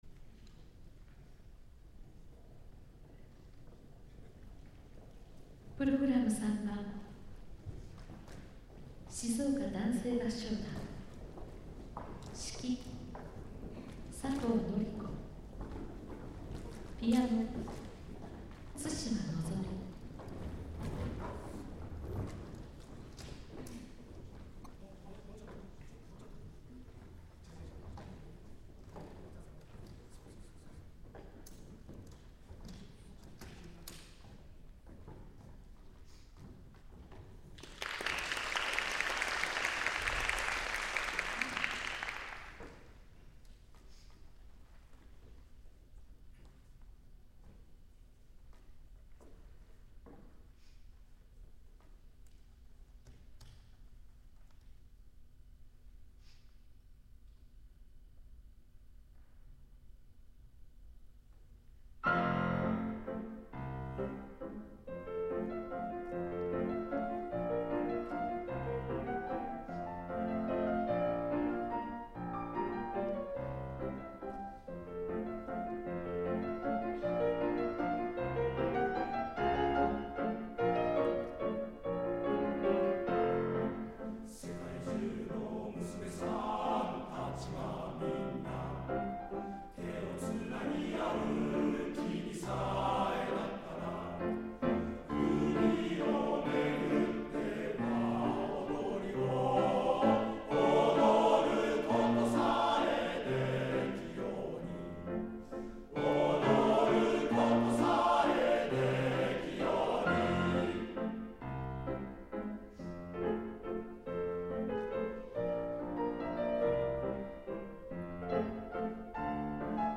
11/1 第65回ふじのくに芸術祭合唱コンクール
演奏の音源はこちら   男声合唱組曲　月下の一群～輪の踊り、秋の歌